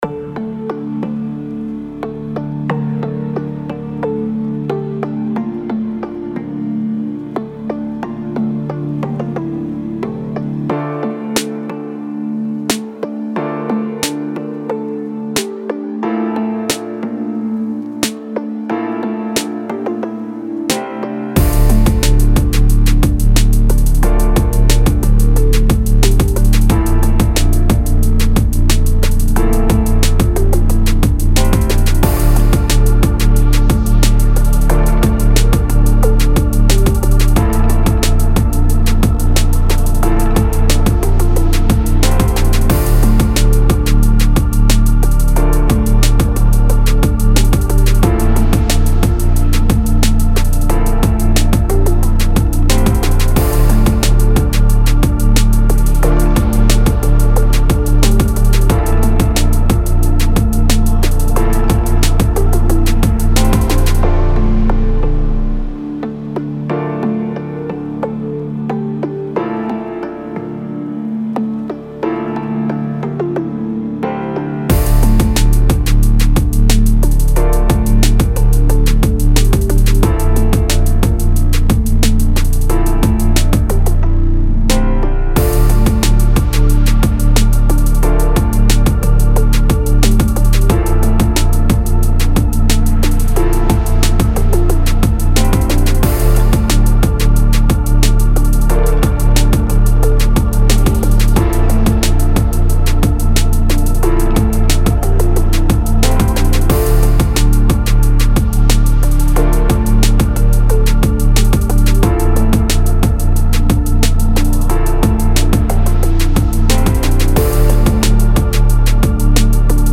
Tag: emotional